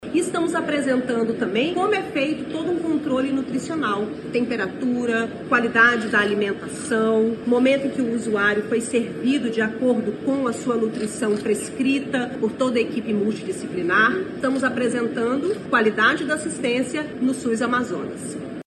Sonora-2-Nayara-Maksoud.mp3